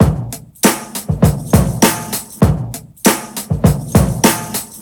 • 99 Bpm Drum Groove D# Key.wav
Free drum loop sample - kick tuned to the D# note. Loudest frequency: 2961Hz
99-bpm-drum-groove-d-sharp-key-AEv.wav